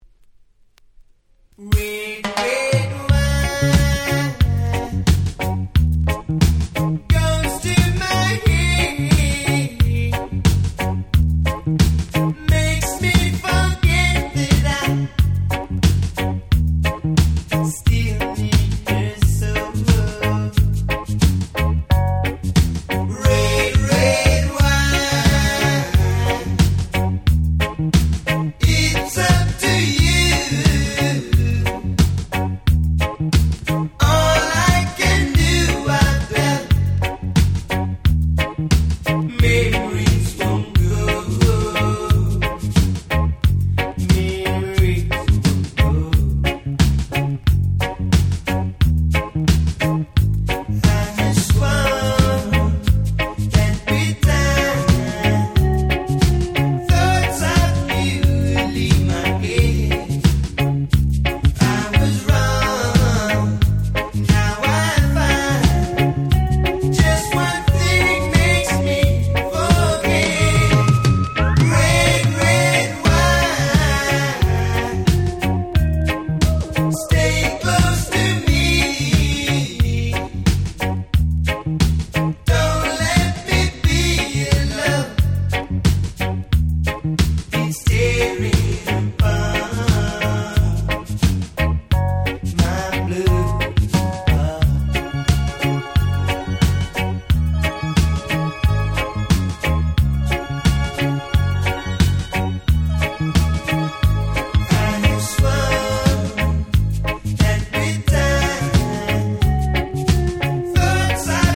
83' Super Hit Reggae !!